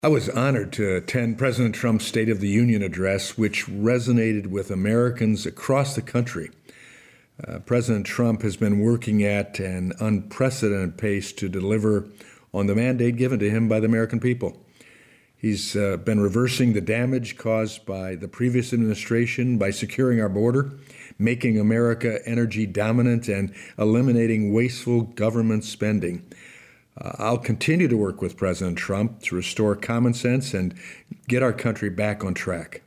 Washington, D.C. – Congressman Tim Walberg, who represents all southern Michigan counties including Lenawee released a statement in response to President Donald Trump’s State of the Union Address Tuesday night. Here was his reaction, with audio courtesy of Walberg’s office…
sotu-reaction-actuality-3-5-25.mp3